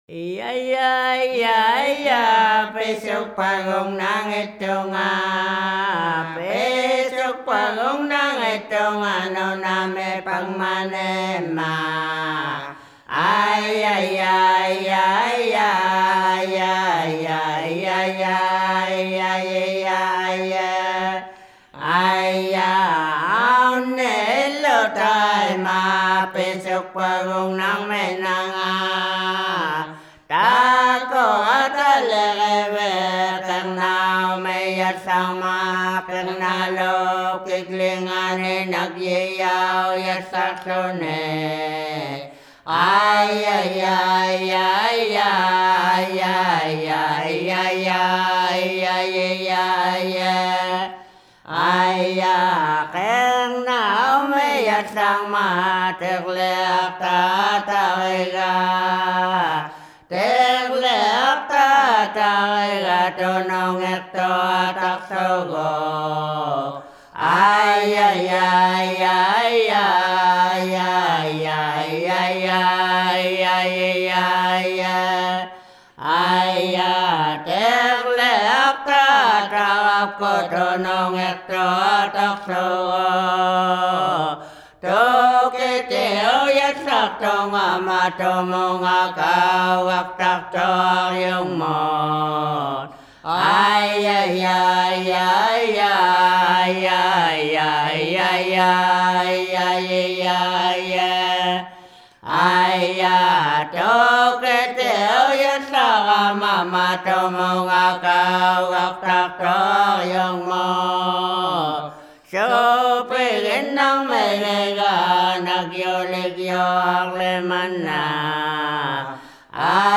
Шаманские песнопения в технике северного горлового пения.
Никаких обработок, никаких сэмплов - чистая аутентика!